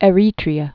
(ĕ-rētrē-ə)